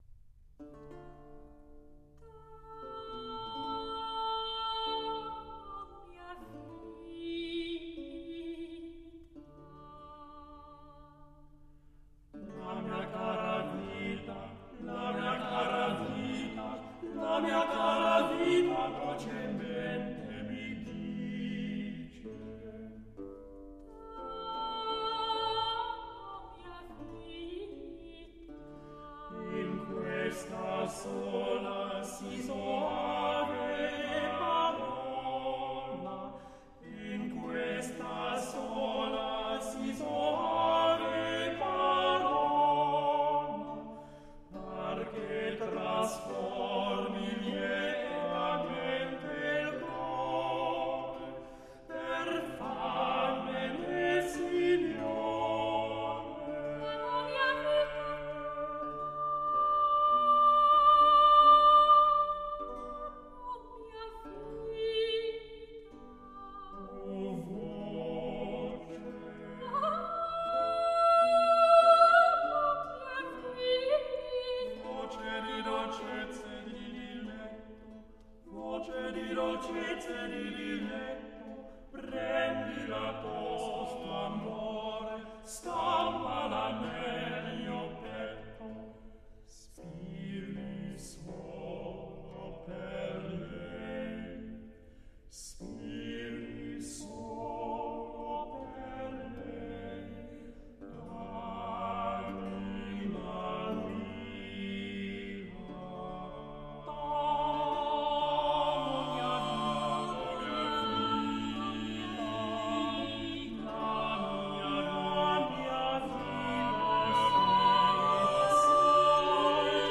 Monteverdi, T'amo mia vita (madrigale Libro V).mp3